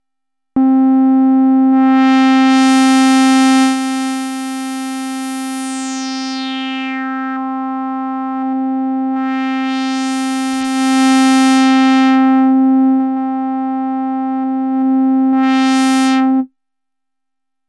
Analogue Synthesizer Module
edit FILTER resonant lowpass filter at 2 / 4 poles slope originally based on EDP WASP vintage synthesizer from 5 Hz to 10 kHz.
filter 2 poles
filter-2poles.mp3